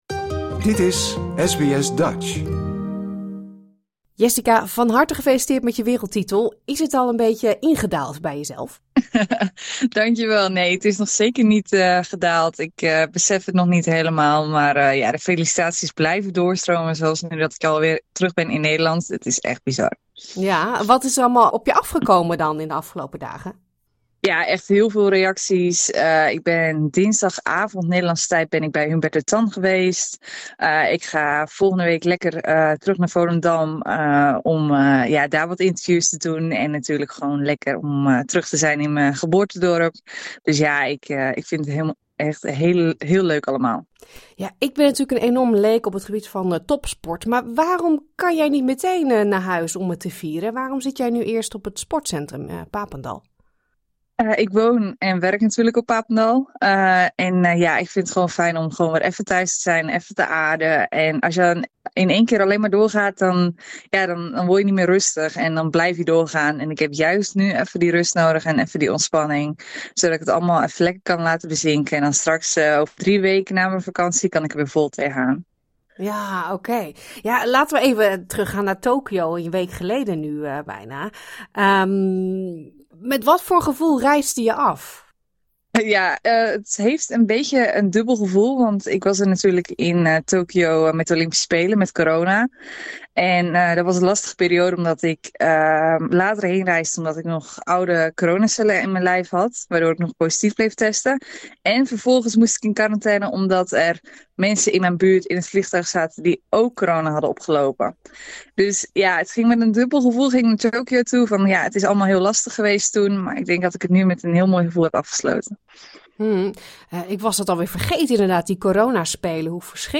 We bellen met de kersverse wereldkampioen Jessica Schilder (26) uit Volendam. Vorige week won zij op het WK atletiek in Tokyo als eerste Nederlandse ooit goud op het onderdeel kogelstoten.